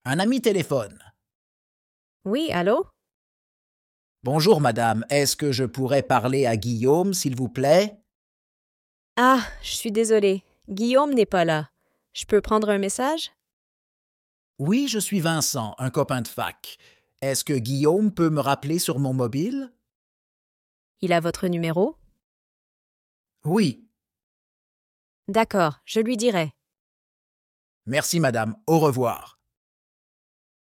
Dialogue en français - un ami téléphone pdf
Dialogue-FLE-un-ami-telephone.mp3